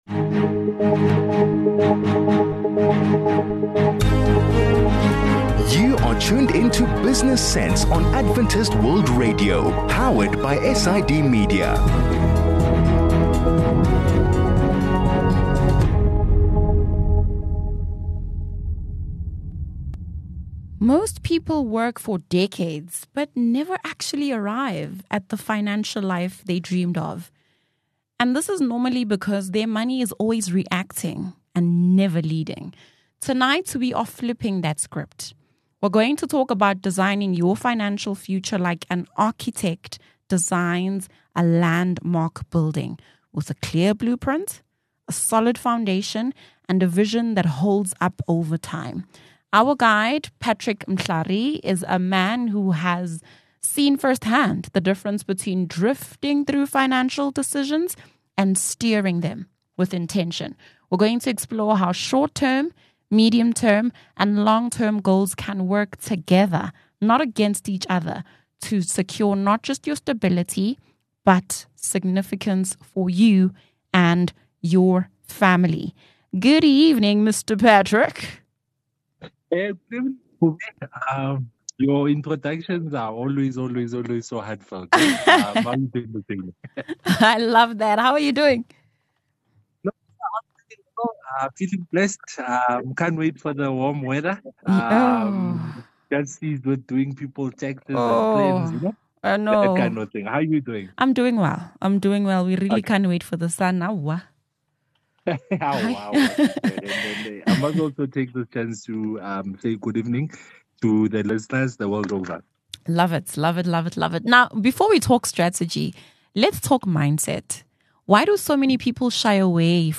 In this conversation, we want to share tips on setting financial goals that align with your needs and aspirations.